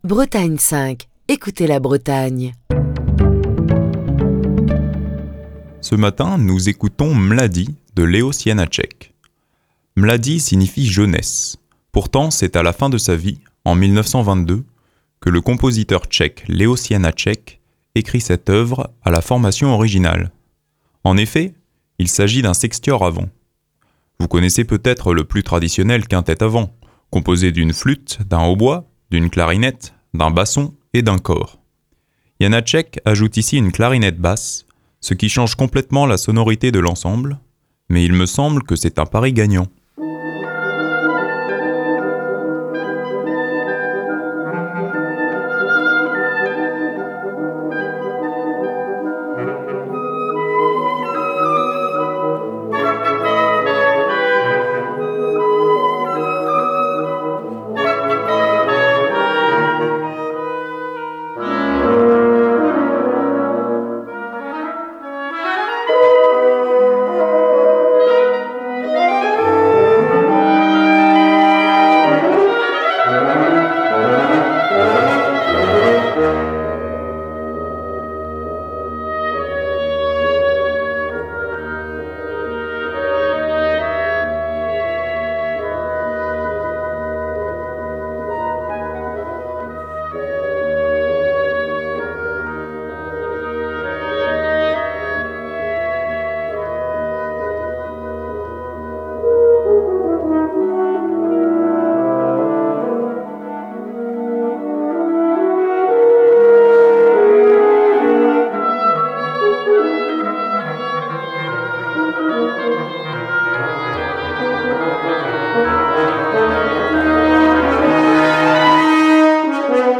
clarinette